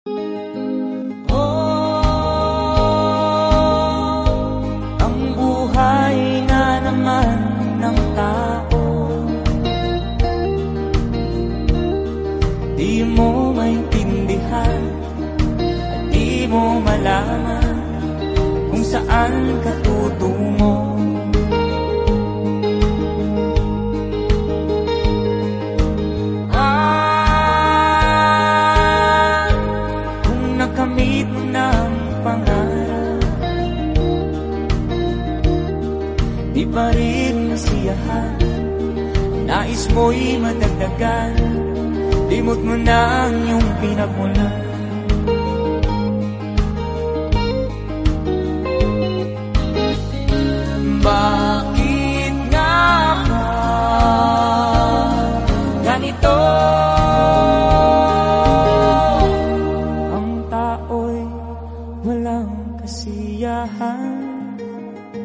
Transporting sacks of corn by horseback.